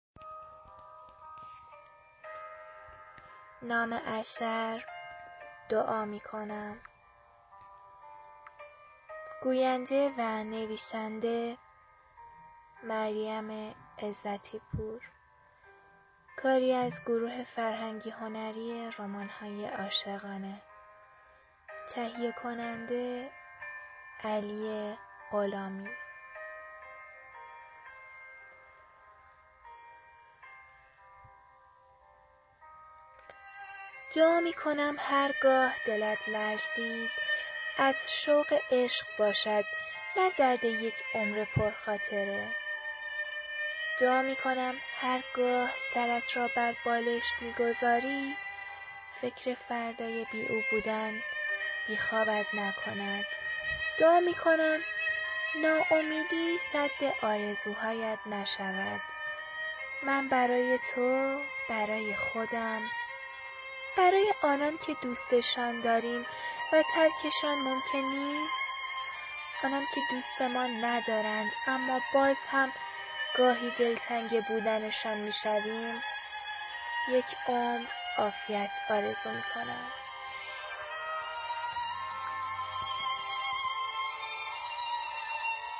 دکلمه صوتی دعا میکنم